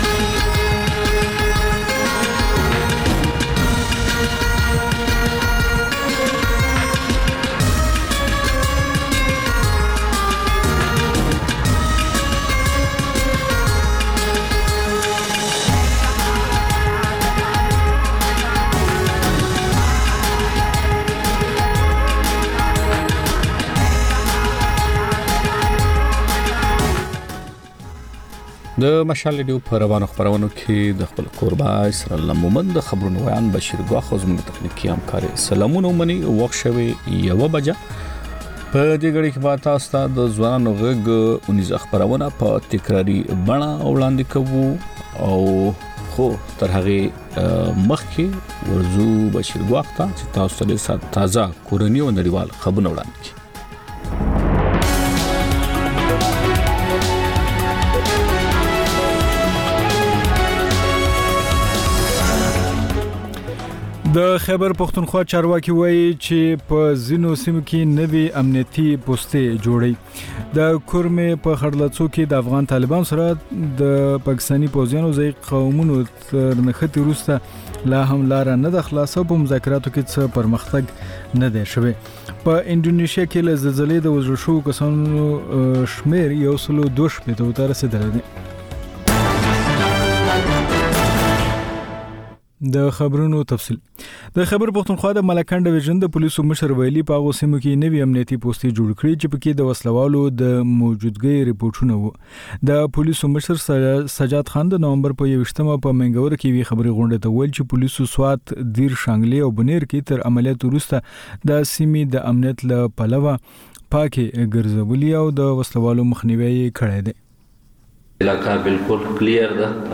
د مشال راډیو لومړۍ ماسپښينۍ خپرونه. په دې خپرونه کې تر خبرونو وروسته بېلا بېل رپورټونه، شننې، مرکې خپرېږي. ورسره یوه اوونیزه خپرونه هم خپرېږي.